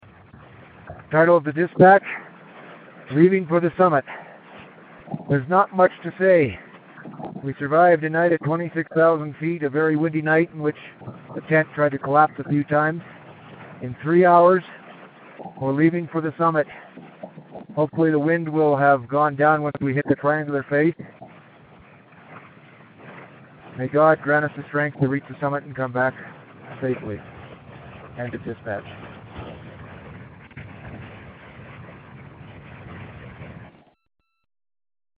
We survived the night at 26,000ft, a very windy night, in which the tents tried to collapse on us a few times. In 3 hours , we are leaving for the summit.